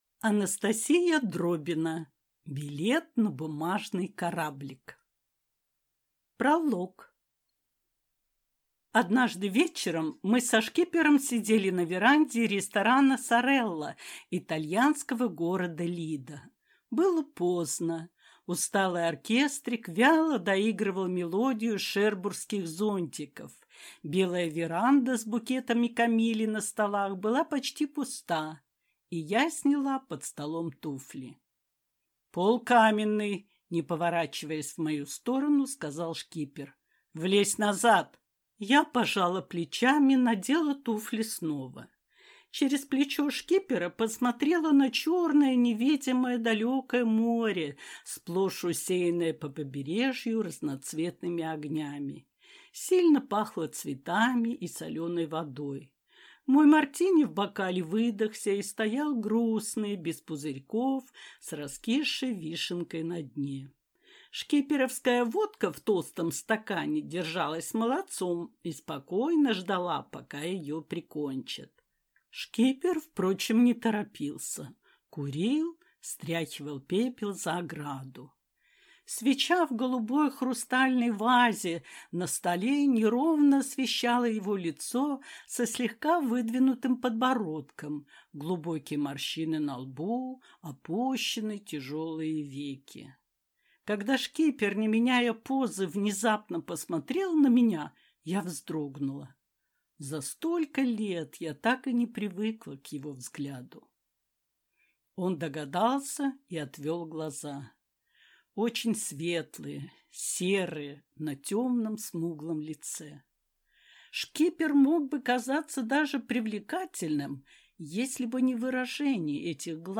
Аудиокнига Билет на бумажный кораблик | Библиотека аудиокниг